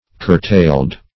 Curtail \Cur*tail"\ (k[u^]r*t[=a]l"), v. t. [imp. & p. p.